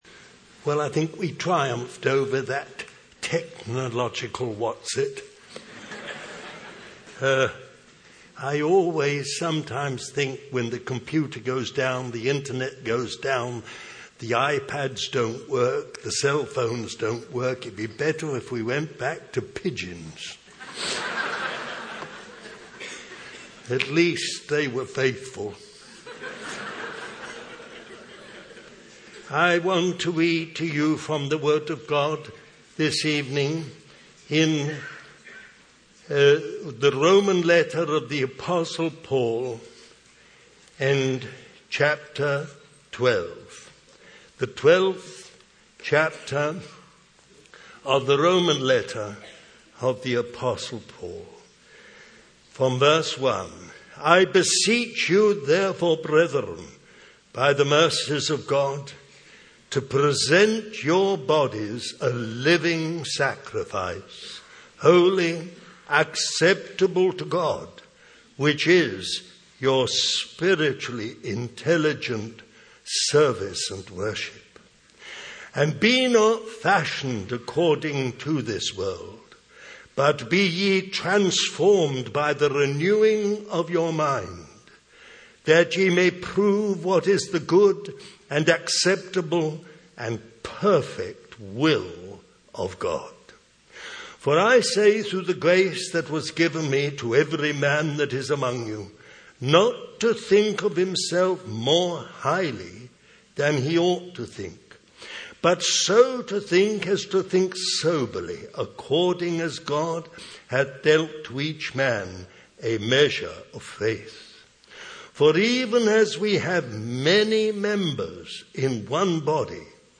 Christian Family Conference July 1, 2014 Romans 12:1-5 / Mark 8:31-35